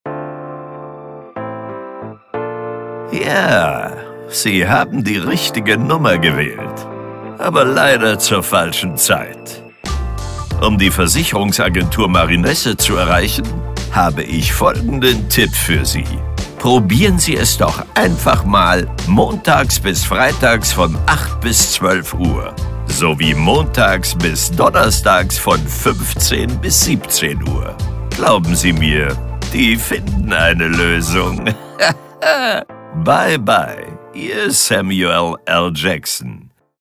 Cool, frech und einfach anders sollten sie sein…
Und so schön können Telefonansagen mit einem Synchronsprecher klingen:
Anrufbeantworter Ansage